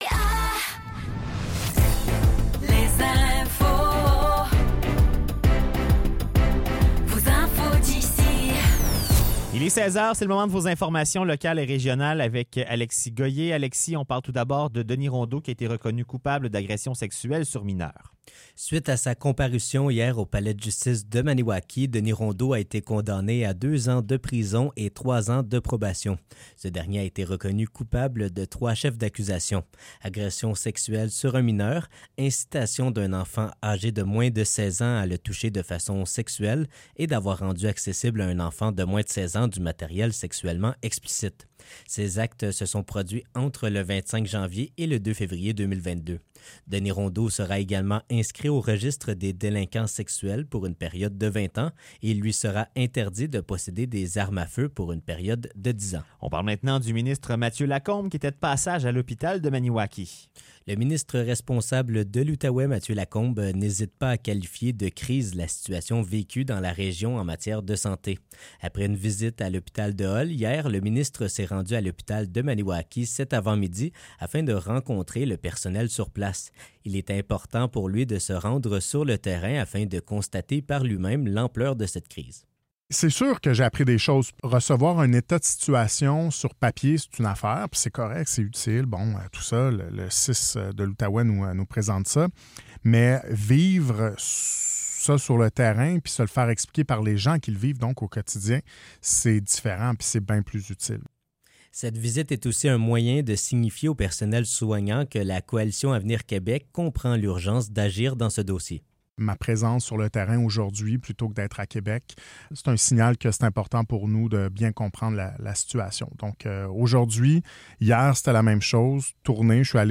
Nouvelles locales - 4 juin 2024 - 16 h